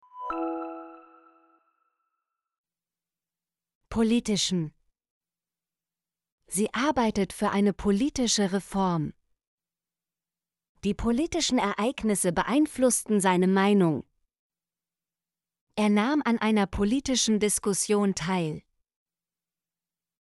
politischen - Example Sentences & Pronunciation, German Frequency List